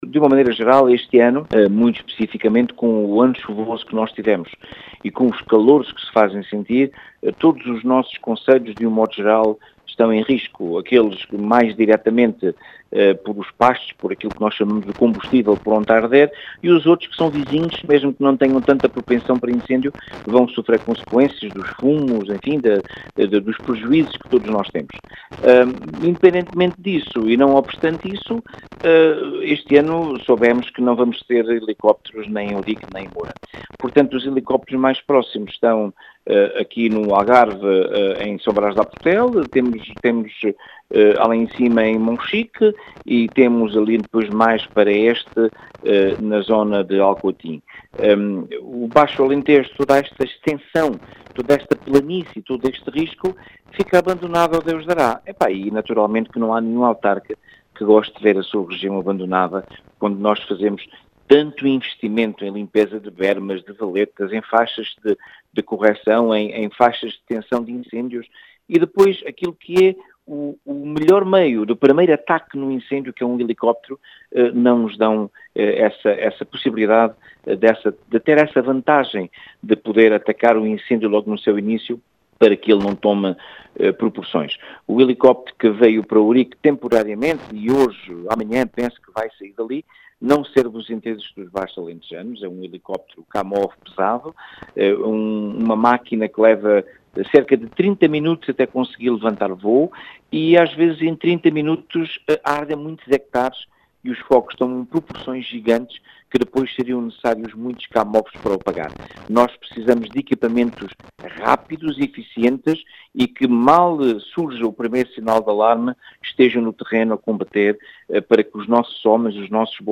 As explicações são de António Bota, presidente da Comunidade Intermunicipal do Baixo Alentejo.